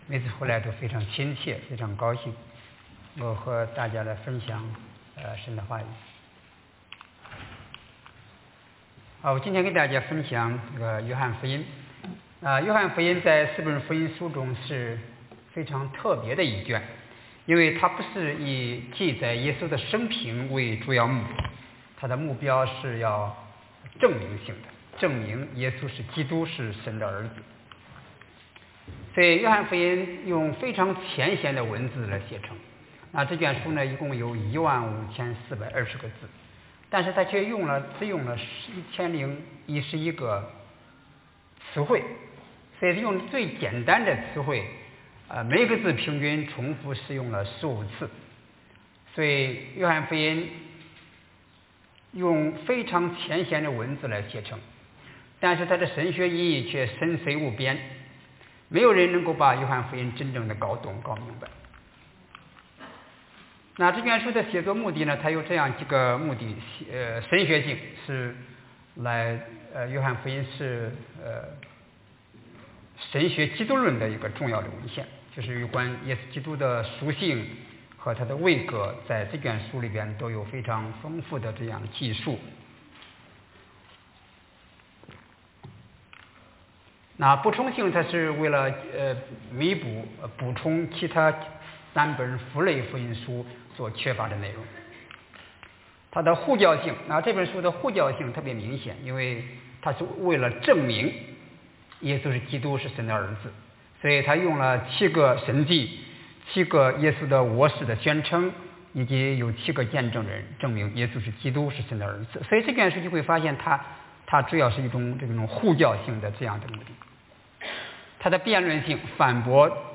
詩篇23 Service Type: Chinese 因祂的名得生命！